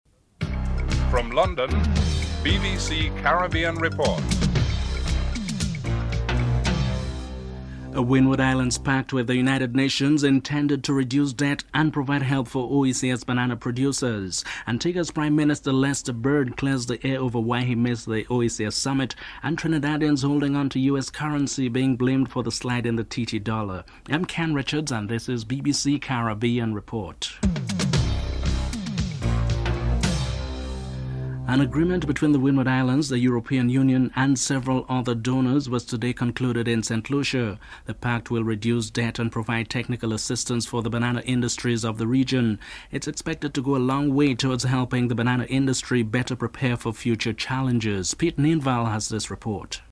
1. Headlines (00:00-00:32)
7. The US currency is being blamed for the slide in the Trinidad and Tobago dollar. Finance Minister Brian Kuei is interviewed (12:49-13:54)